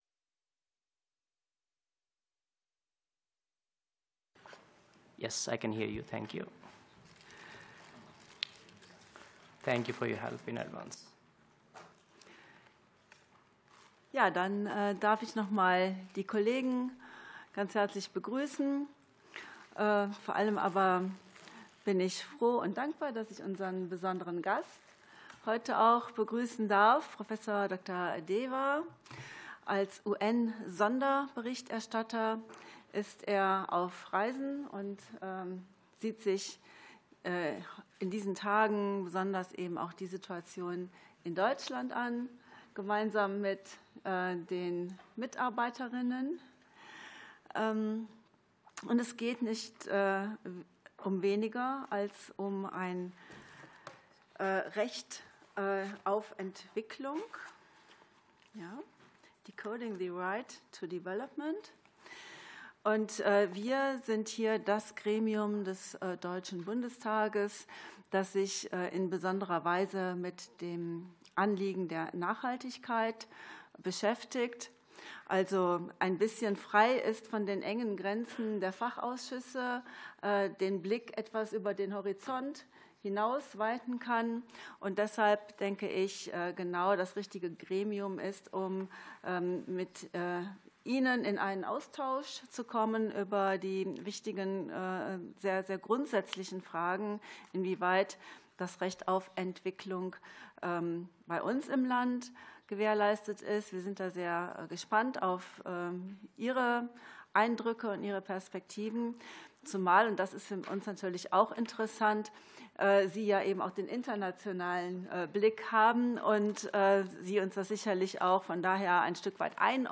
Fachgespräch zum Thema "Recht auf Entwicklung" ~ Ausschusssitzungen - Audio Podcasts Podcast